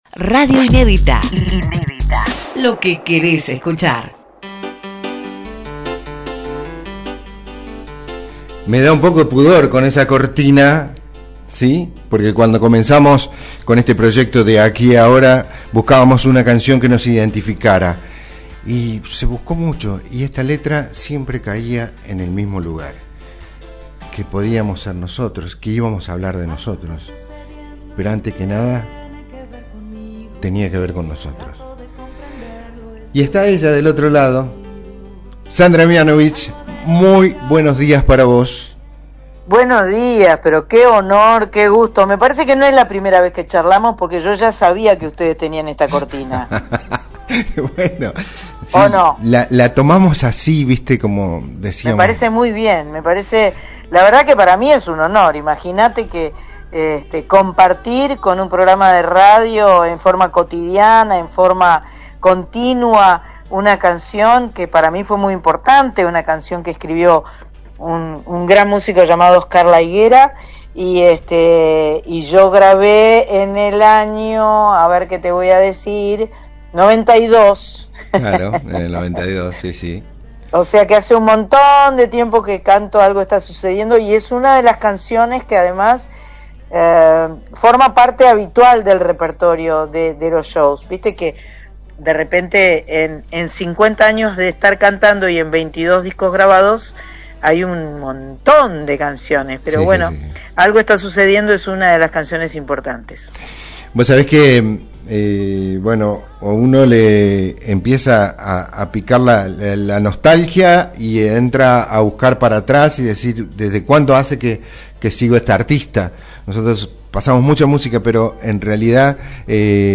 En Aquí & Ahora por Radio Inédita , la artista expresó su entusiasmo por volver a Córdoba, una plaza que definió como “entrañable” y con “identidad propia”.